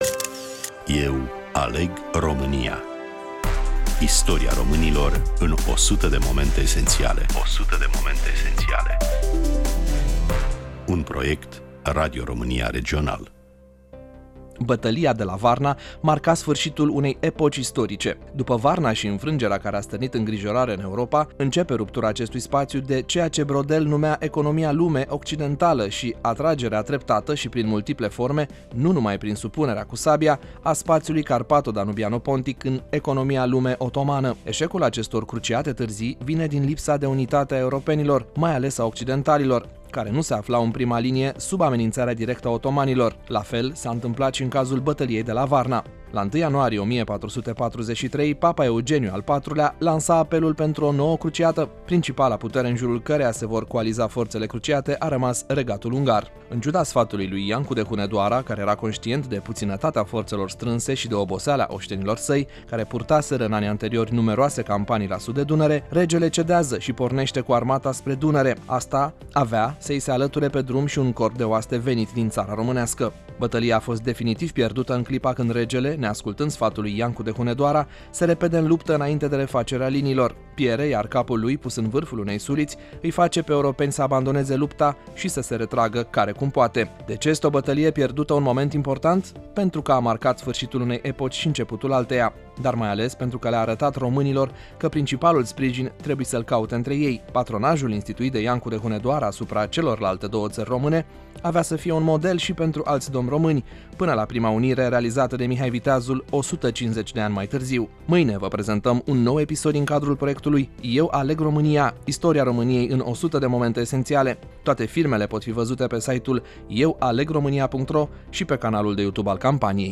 Prezentare
Voice over